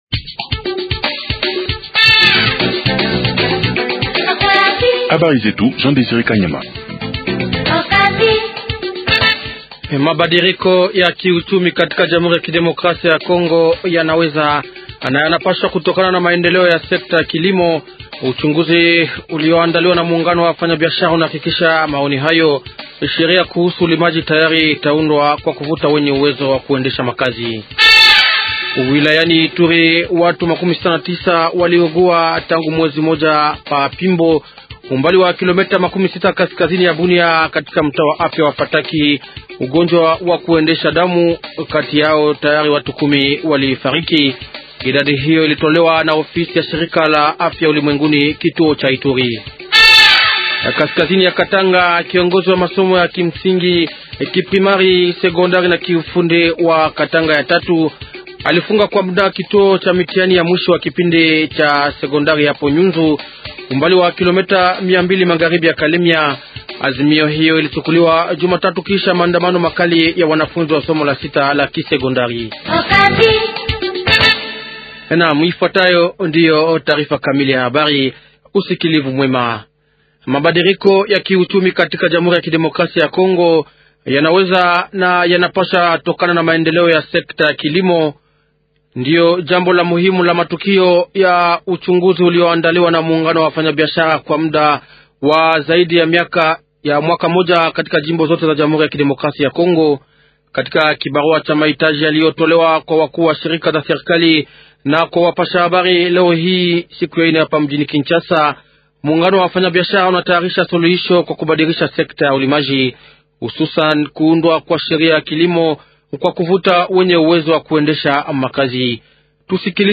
100507-journal swahili soir